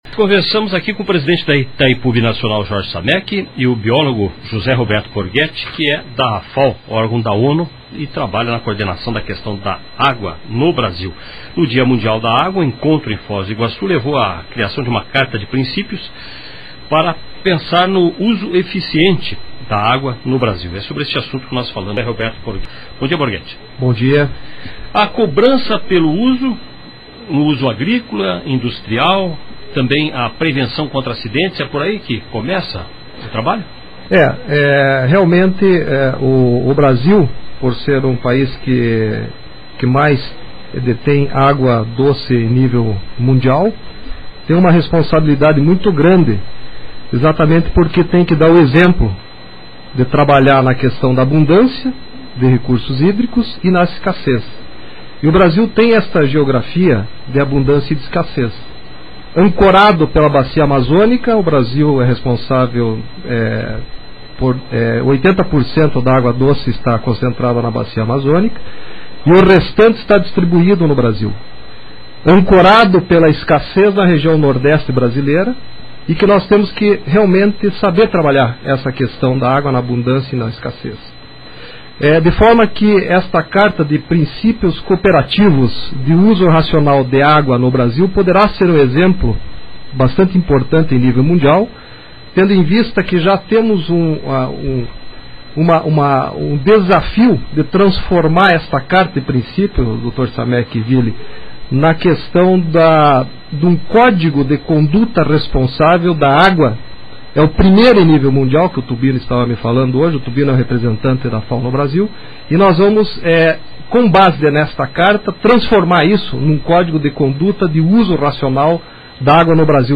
Dia Mundial da Água - Entrevista CBN